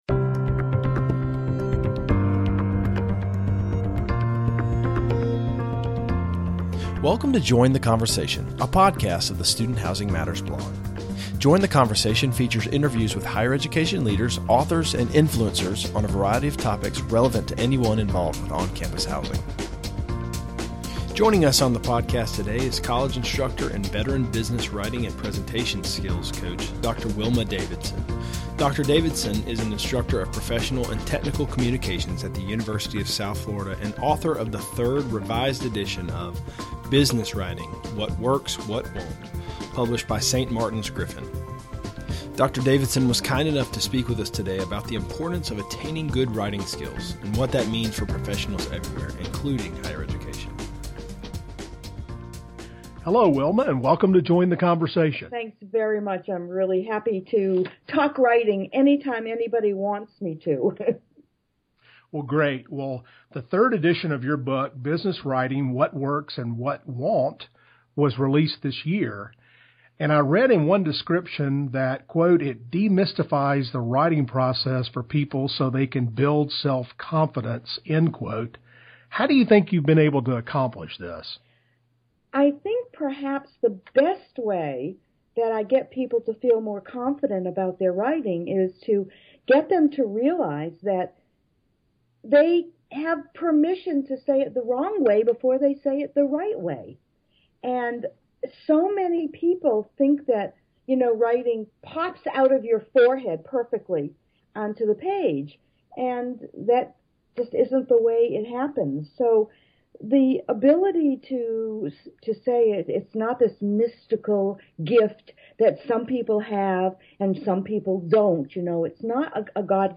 If you are a person who finds writing difficult; if you are a parent who wants to help your children write better; and if you are a student who just wants to write better, this interview can help!